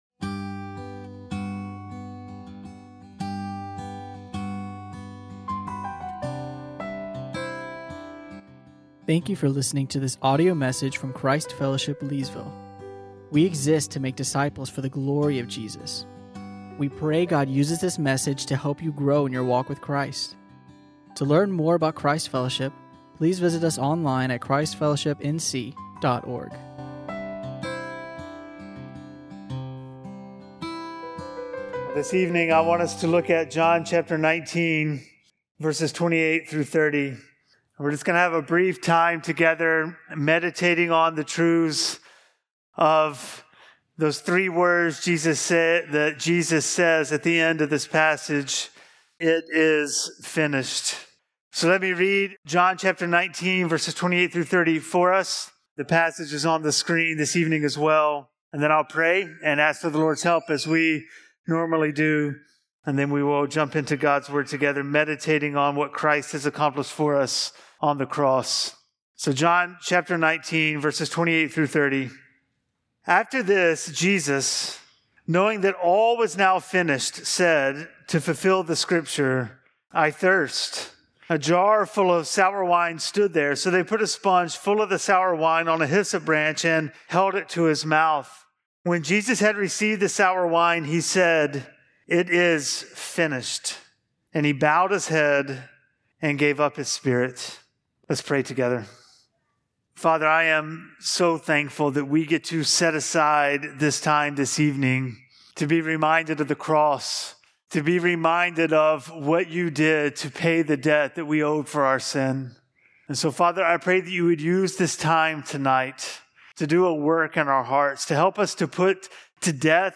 Luke 19:28-30 (Good Friday)